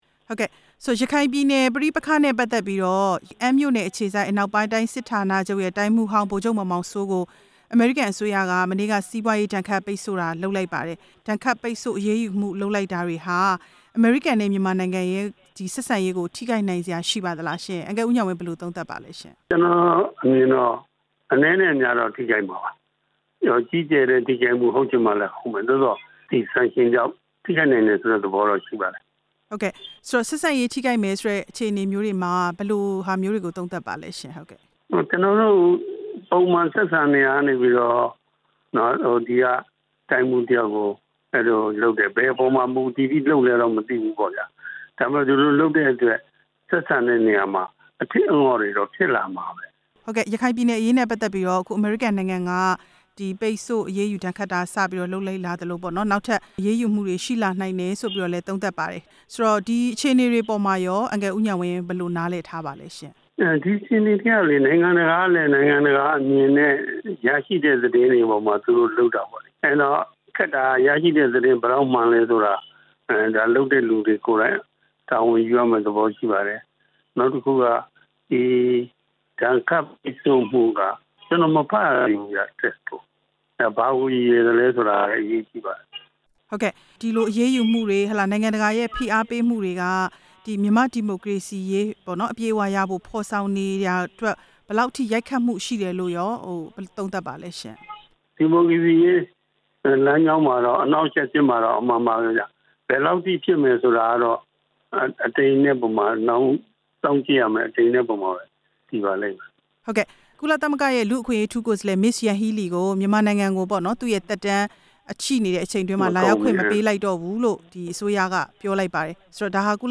အမ်းမြို့နယ်အခြေစိုက် အနောက်ပိုင်းတိုင်း စစ်ဌာနချုပ်တိုင်းမှူးဟောင်း ဗိုလ်ချုပ်မောင်မောင်စိုးကို ရခိုင်ပြည်နယ် ပြဿနာထဲ လူ့အခွင့်အရေး ချိုးဖောက်မှုတွေမှာ တာဝန်ရှိတဲ့အတွက်ဆိုပြီး အမေရိကန်က ဒဏ်ခတ်အရေးယူမှုအသစ် ချမှတ်လိုက်တာနဲ့ ပတ်သက်ပြီး ဆက်သွယ်မေးမြန်းစဉ် အခုလို သုံးသပ်လိုက်တာပါ။